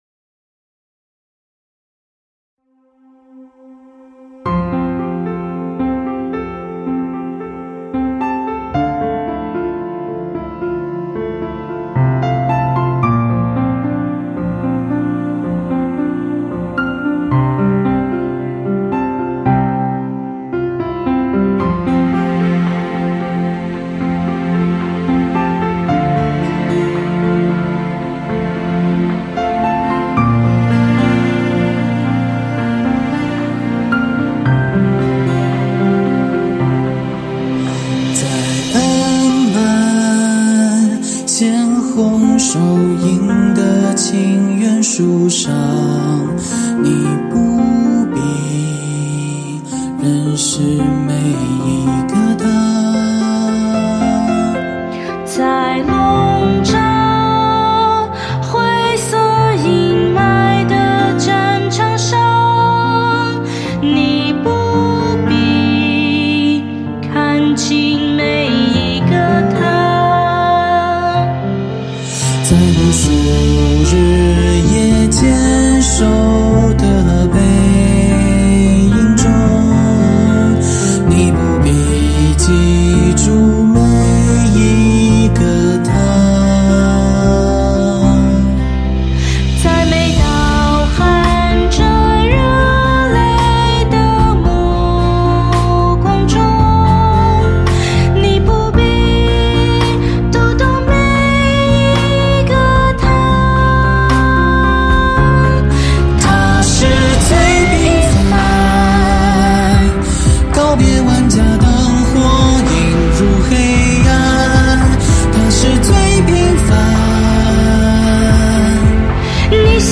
声乐作品
身在异地的50多位学院师生在家中跟随伴奏音频，克服各种困难，在祖国的四面八方录制了自己的人声版本。